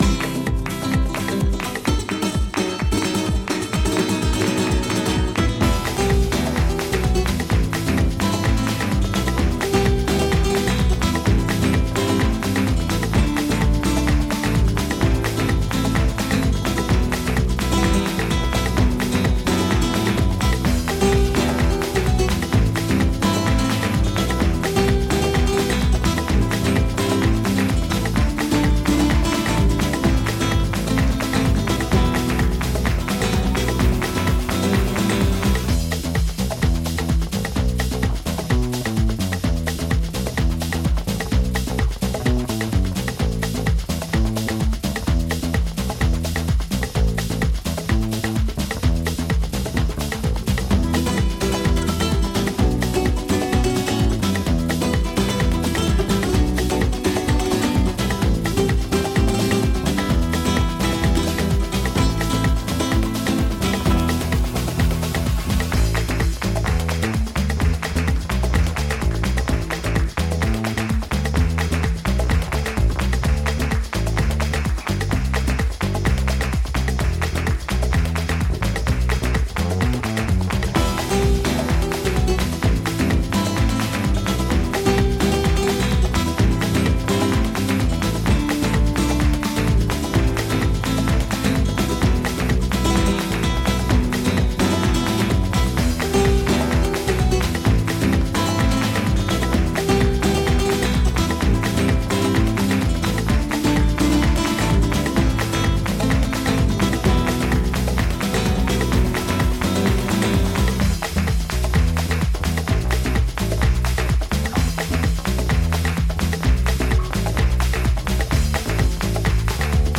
ファンクするベースラインとパーカッション、スパニッシュ・ギターの応酬に両手が上がる